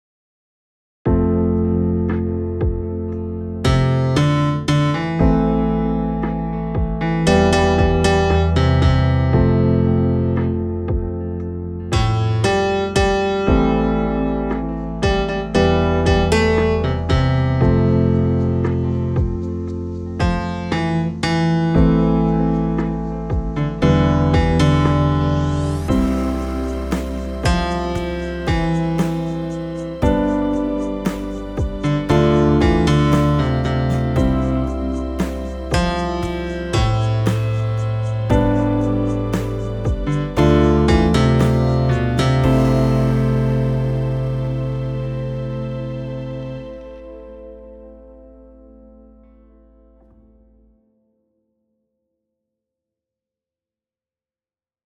guide for bass and baritone